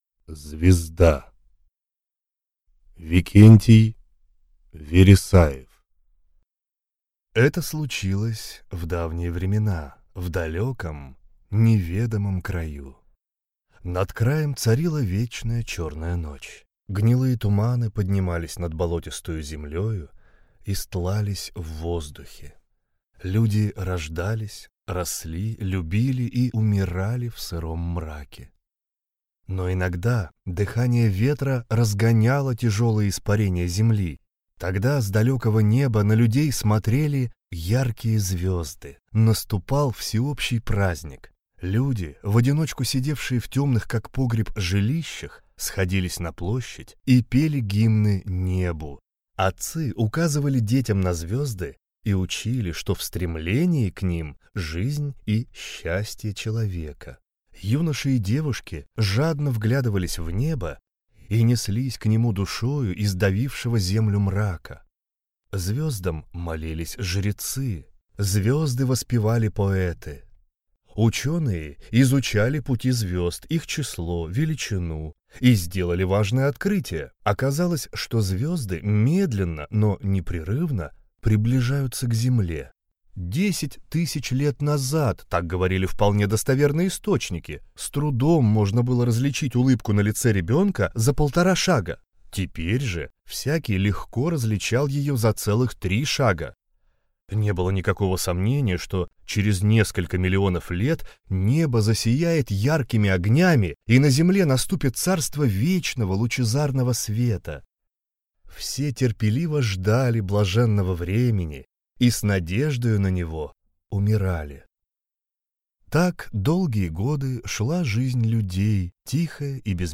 Аудиокнига Звезда | Библиотека аудиокниг